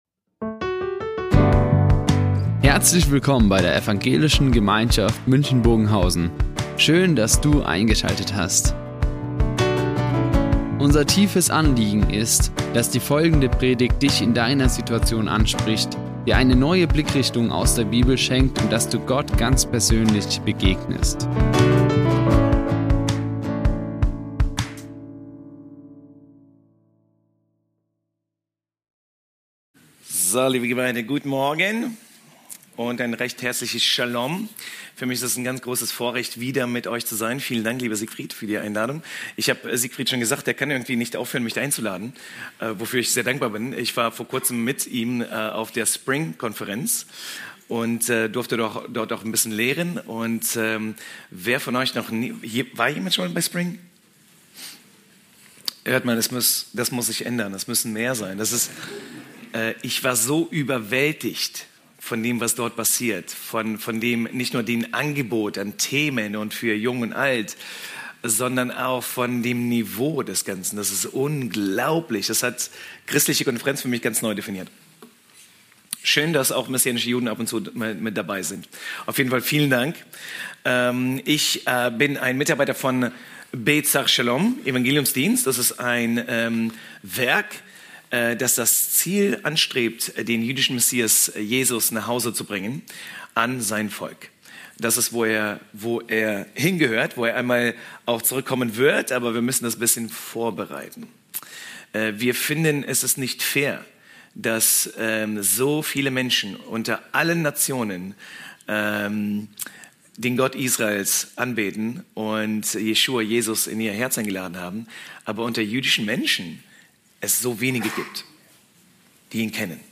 Die Aufzeichnung erfolgte im Rahmen eines Livestreams.